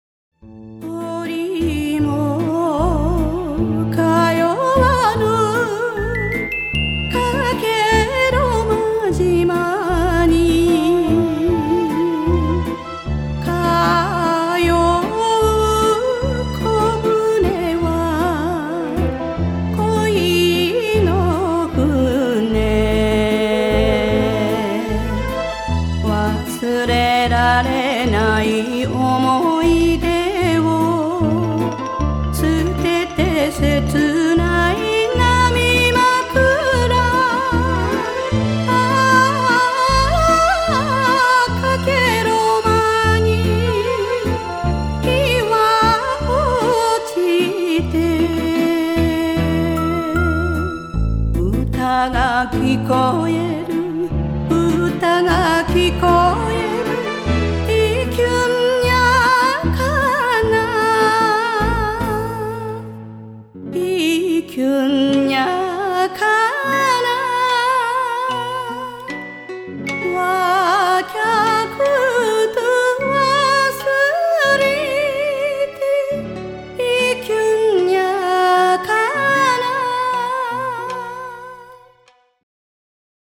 奄美歌謡